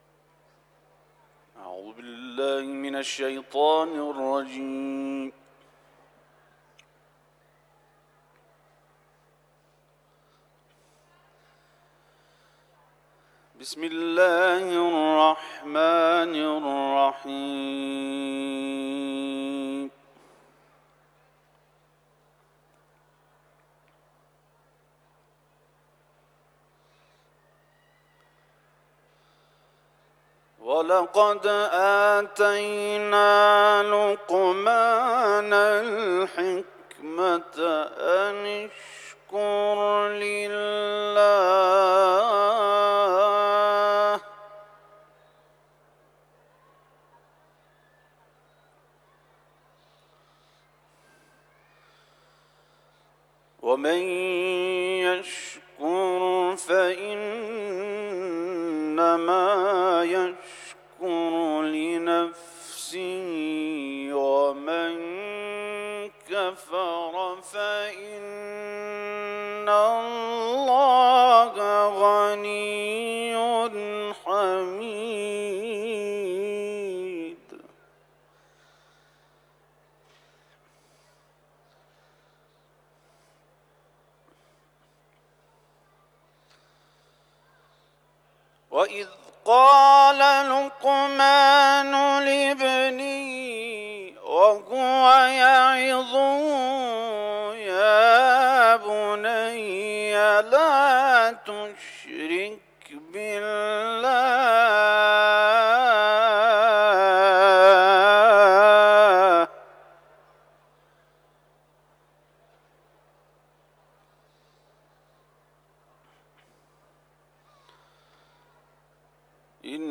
صوت این تلاوت در قسمت زیر قابل پخش است.
تلاوت